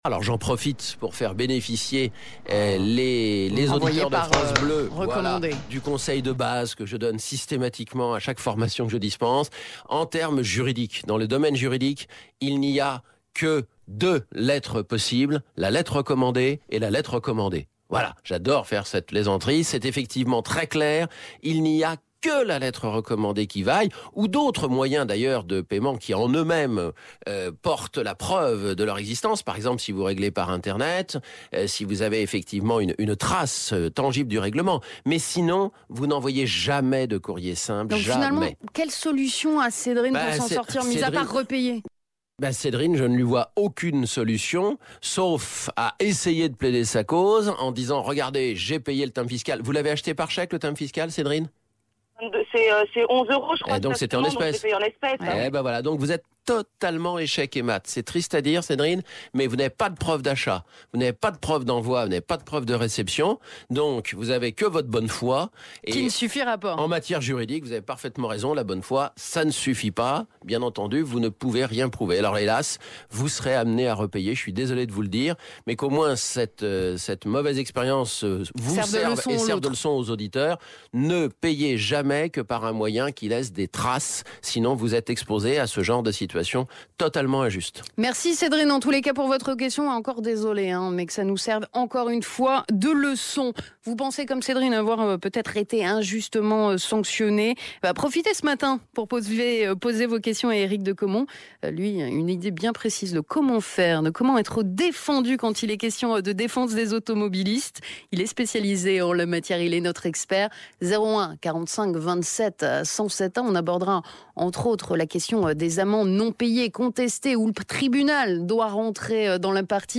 répond aux auditeurs de France Bleu